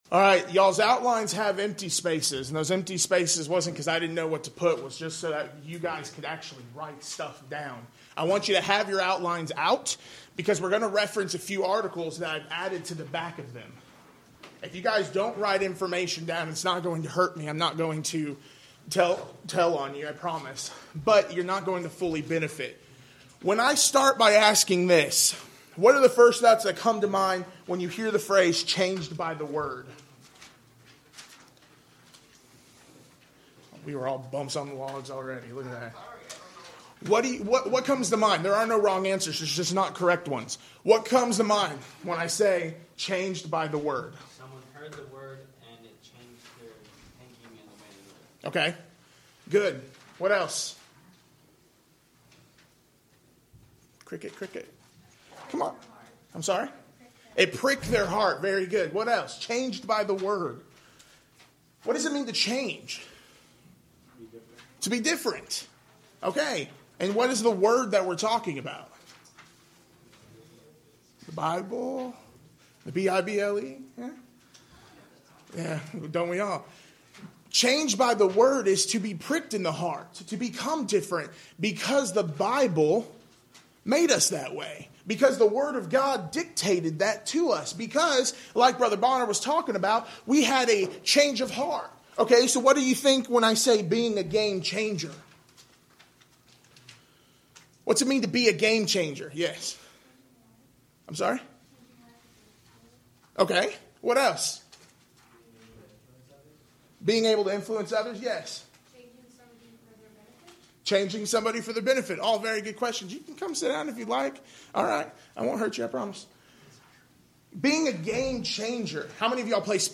Alternate File Link File Details: Series: Discipleship University Event: Discipleship University 2013 Theme/Title: Dead or Alive: Lessons about faith from a man who served Jesus.
Youth Sessions